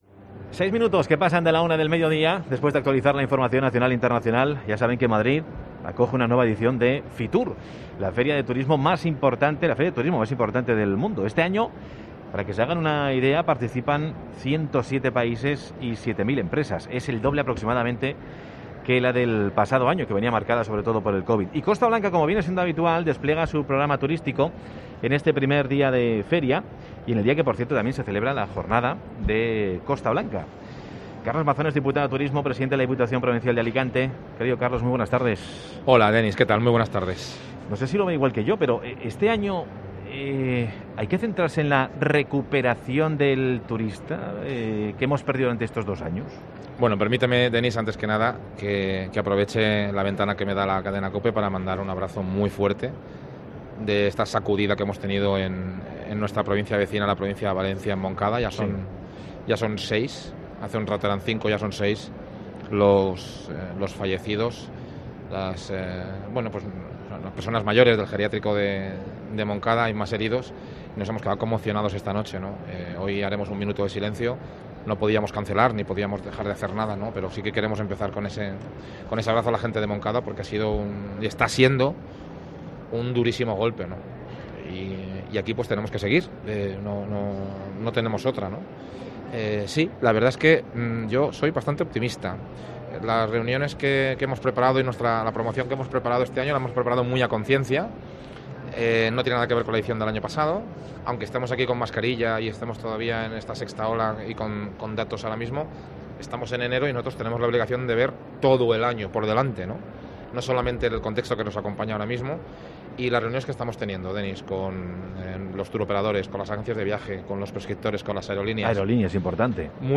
Entrevista a Carlos Mazón desde Fitur